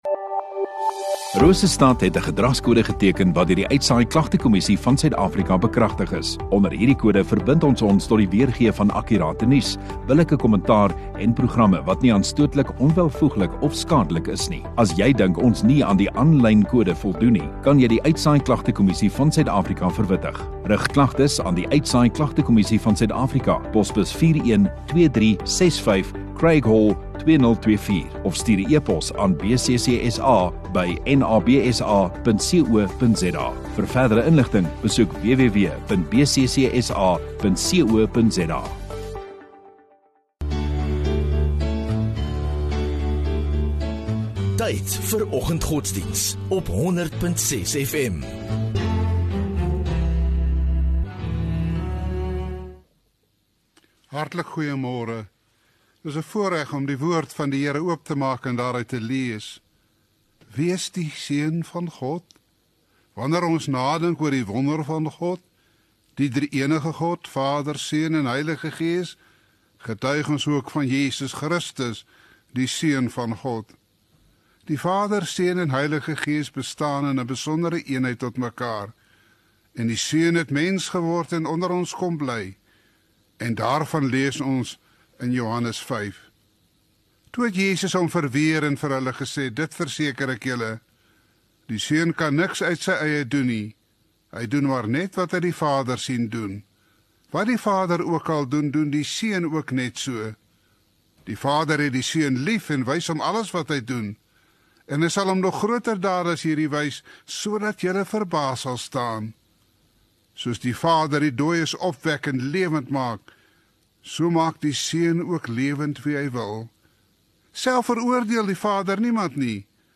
16 Sep Dinsdag Oggenddiens